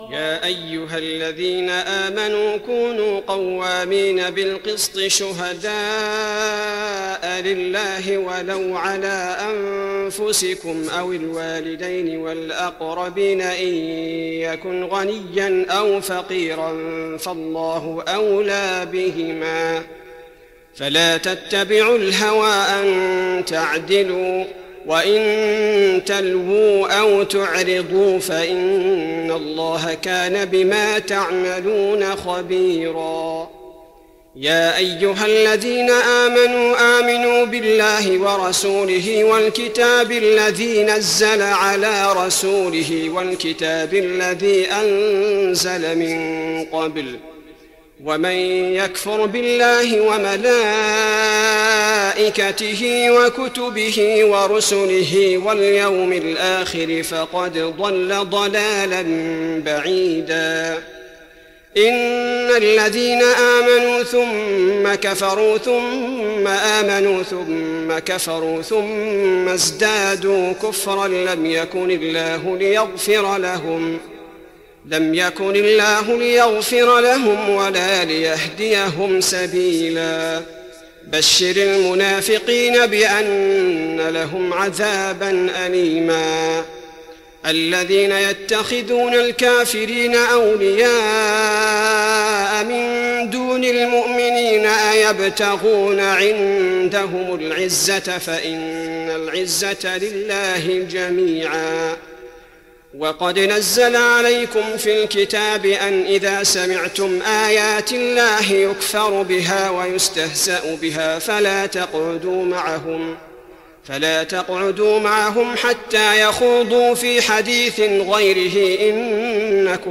تهجد رمضان 1415هـ من سورة النساء (135-176) Tahajjud Ramadan 1415H from Surah An-Nisaa > تراويح الحرم النبوي عام 1415 🕌 > التراويح - تلاوات الحرمين